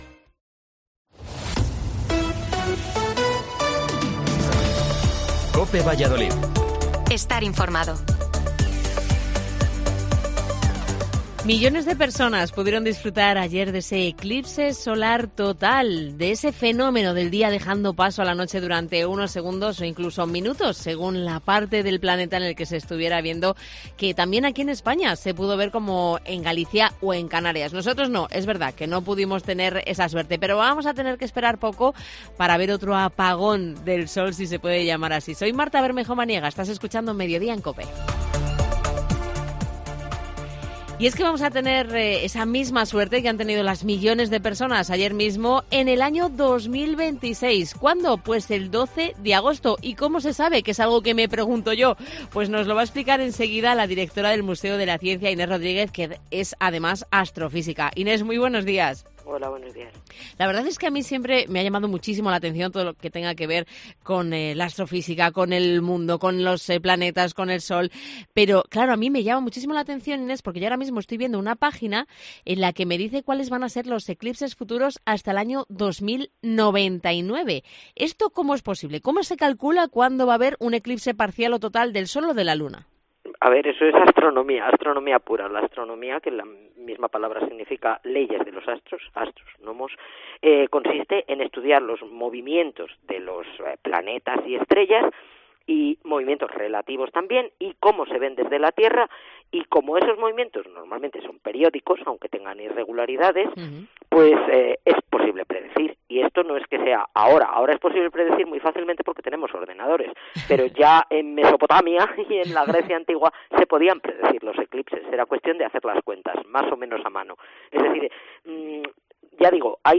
En Mediodía en COPE hemos hablado con la astrofísica